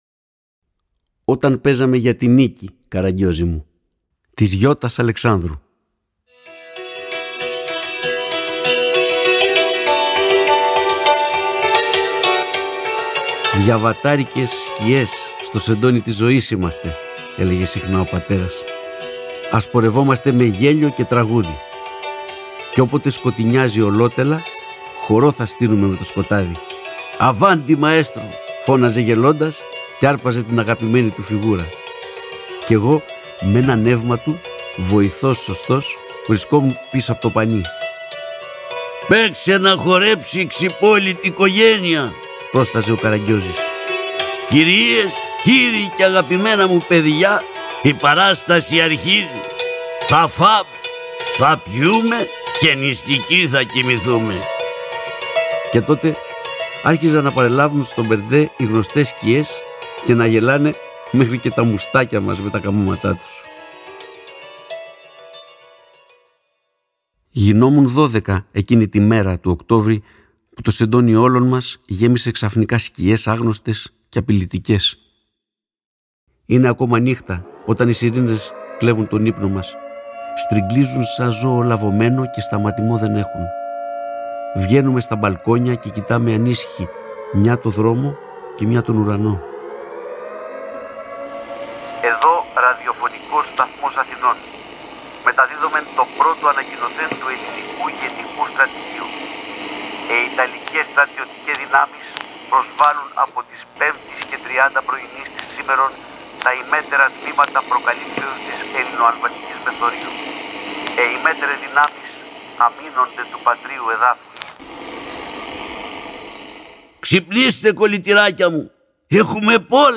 Περιέχει Qrcode με την αφήγηση